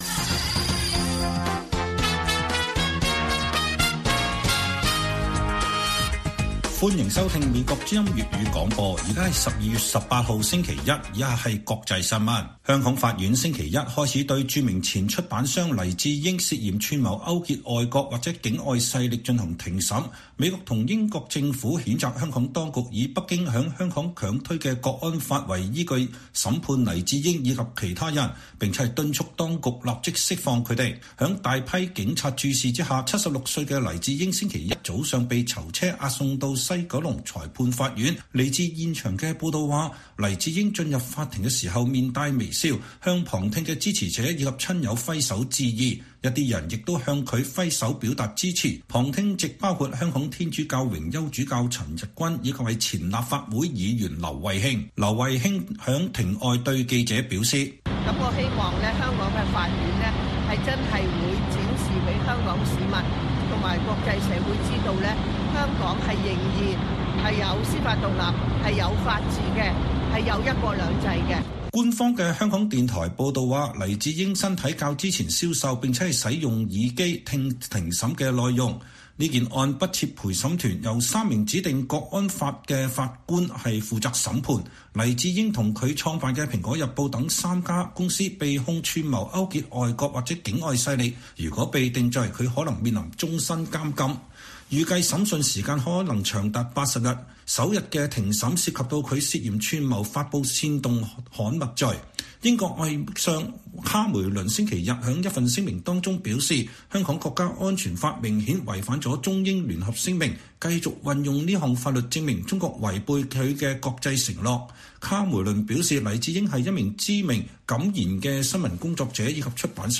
粵語新聞 晚上10-11點: 黎智英涉嫌違反國安法案首日開庭